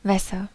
Ääntäminen
Ääntäminen Tuntematon aksentti: IPA: /ˈvɛsɐ/ Haettu sana löytyi näillä lähdekielillä: saksa Käännöksiä ei löytynyt valitulle kohdekielelle. Wässer on sanan Wasser monikko.